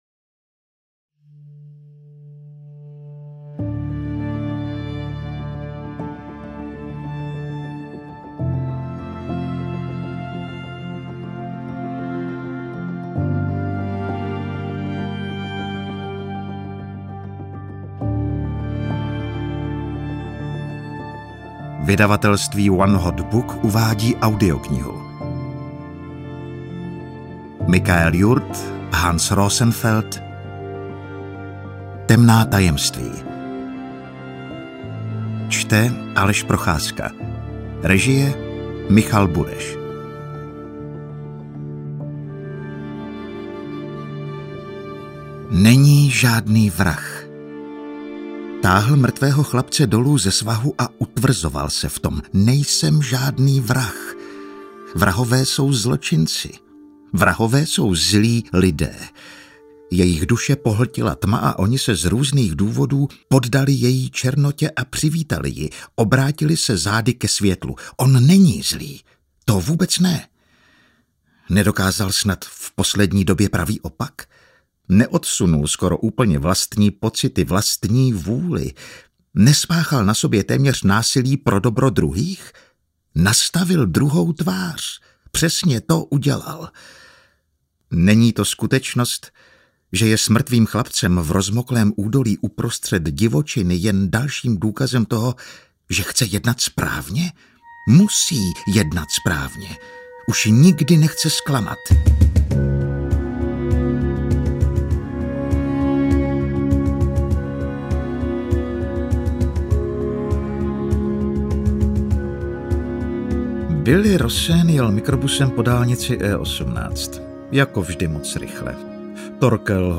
Audio kniha3x Bergman #1
Ukázka z knihy